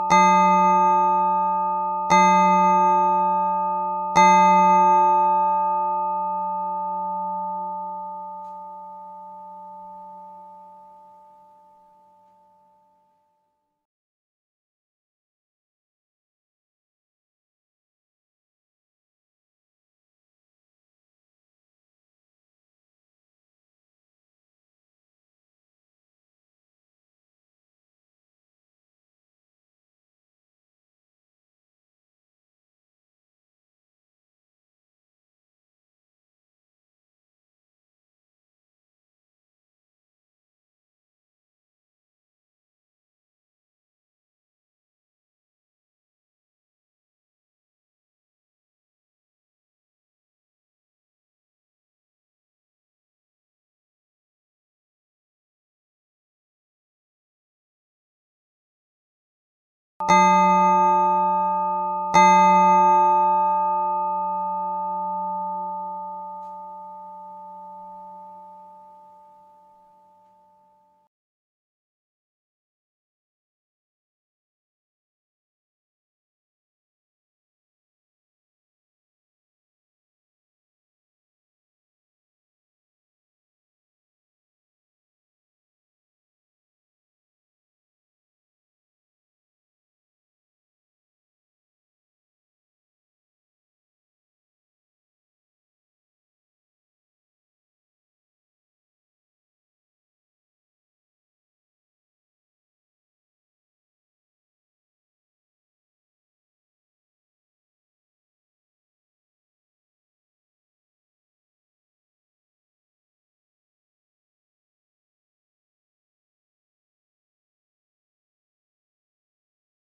GongPrzedSpotkaniem.mp3